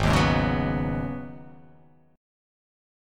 Abm11 chord